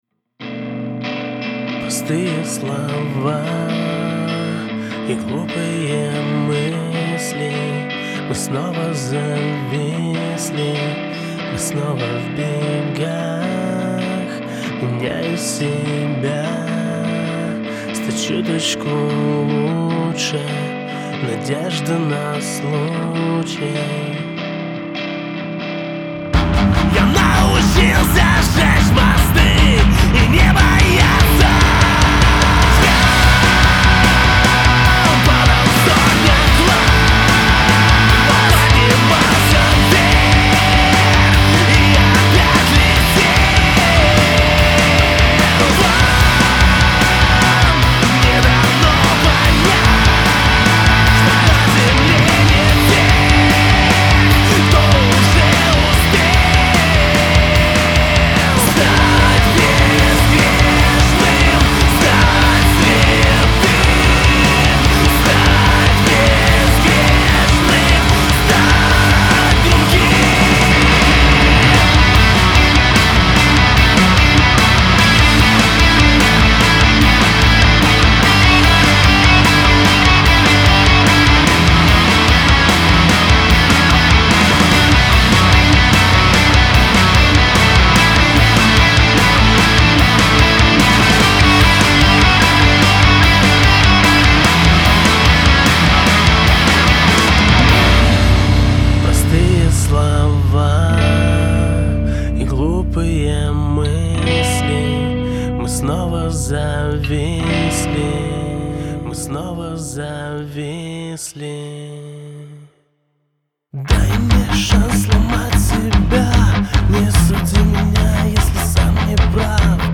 Rock/alternative/post-grunge
Интересует Ваше мнение по поводу микса, только учусь подобное делать, с удовольствием выслушаю критику